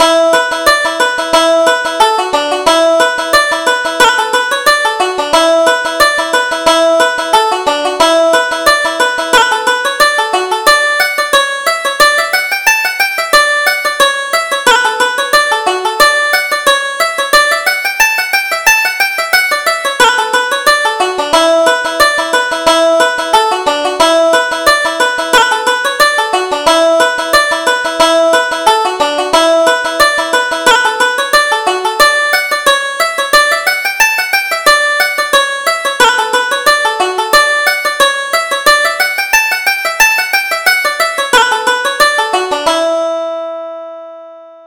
Reel: Drowsy Maggie